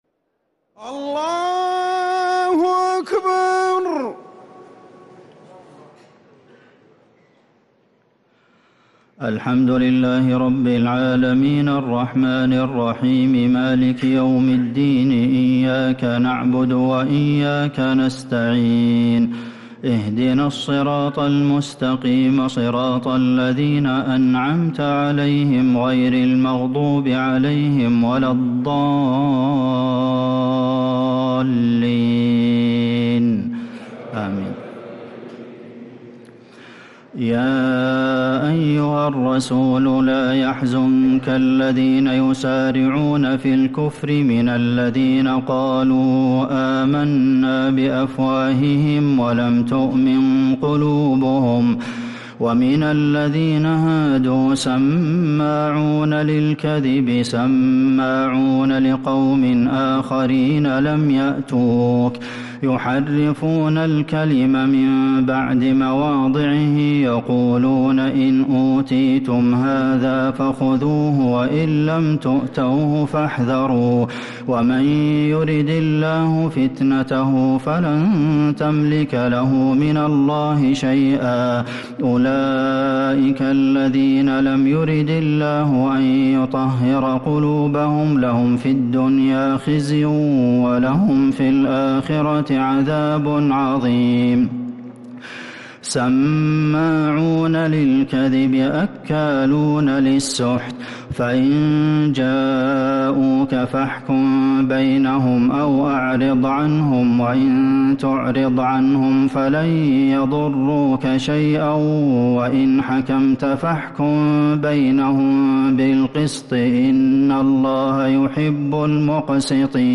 تراويح ليلة 8 رمضان 1446هـ من سورة المائدة {41-81} Taraweeh 8th night Ramadan 1446H Surat Al-Maidah > تراويح الحرم النبوي عام 1446 🕌 > التراويح - تلاوات الحرمين